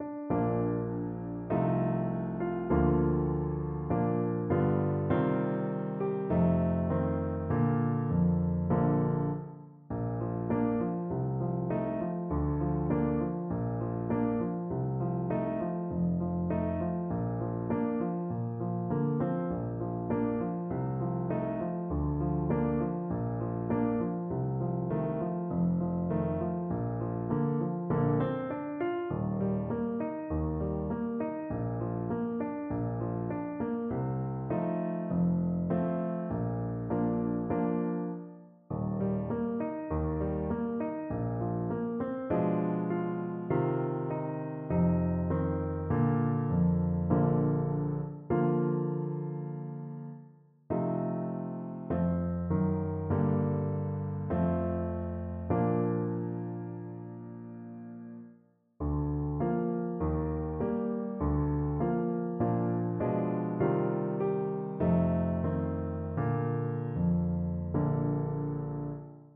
Alto Saxophone
4/4 (View more 4/4 Music)
Andantino (View more music marked Andantino)